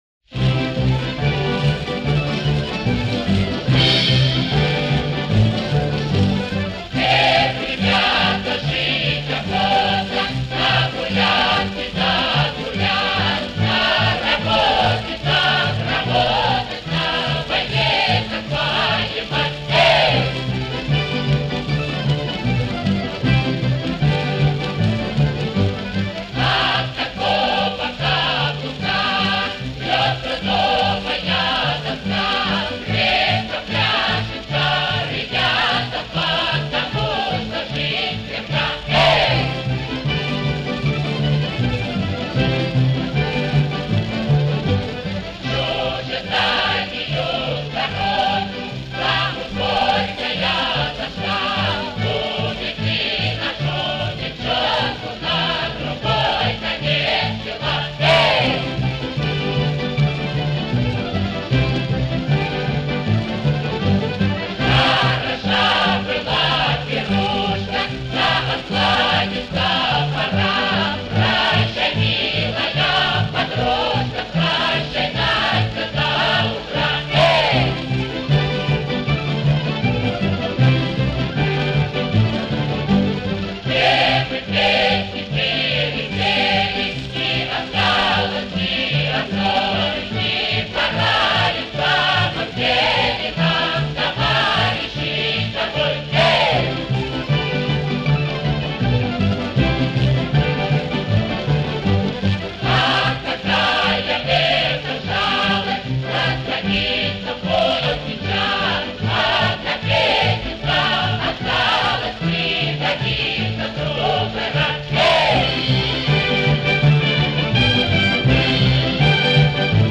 С русским народным оркестром